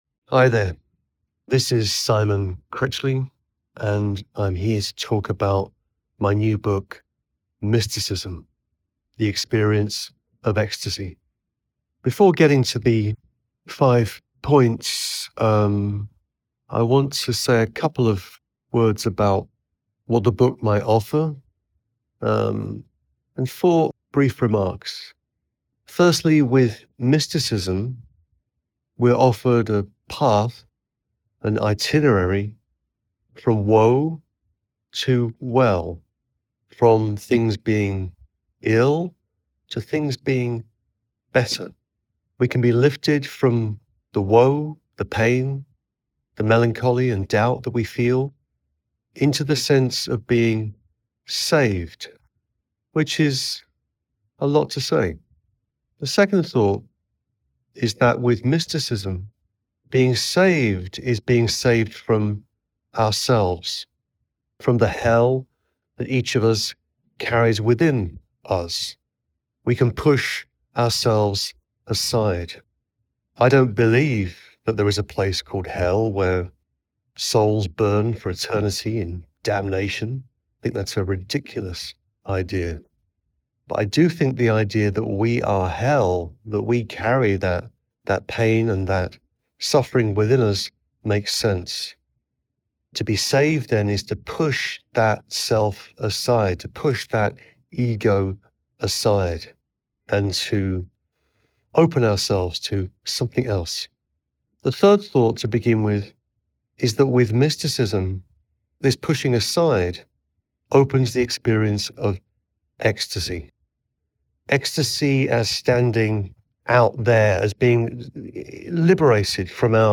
Listen to the audio version—read by Simon himself—in the Next Big Idea App.